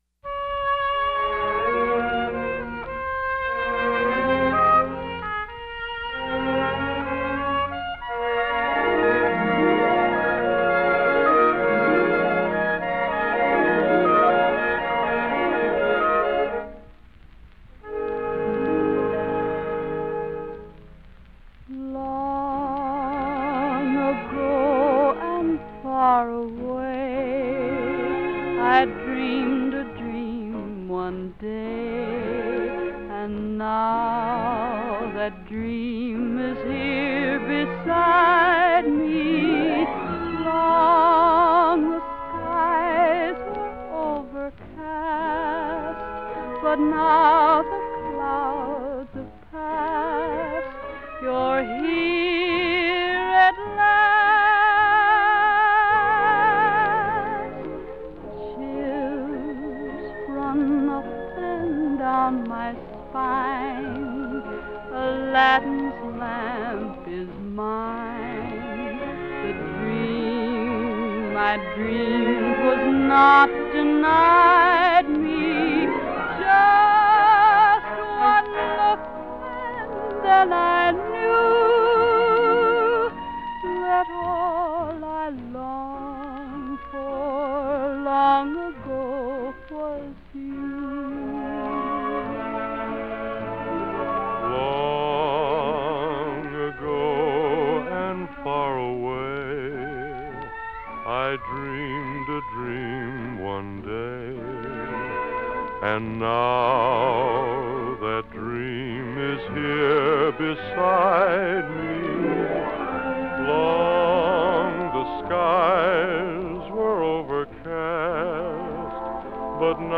1944   Genre: Soundtrack   Artists